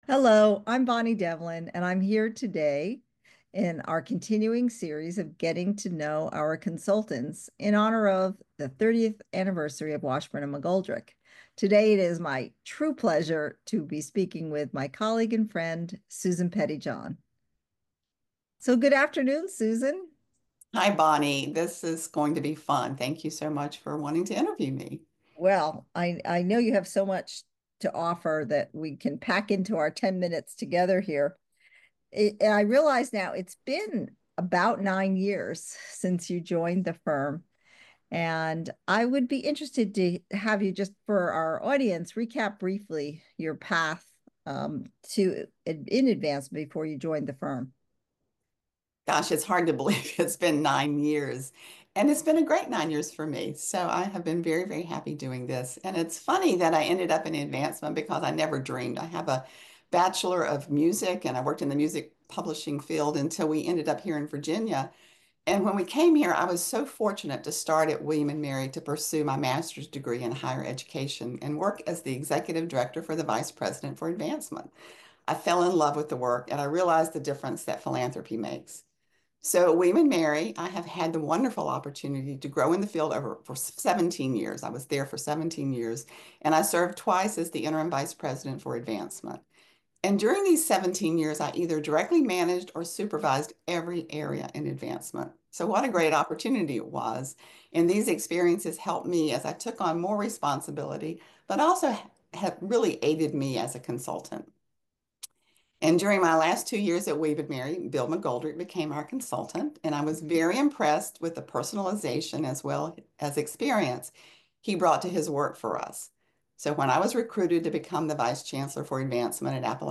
As part of WASHBURN & MCGOLDRICK'S 30th Anniversary year, each month we are featuring a brief conversation with one of our consultants.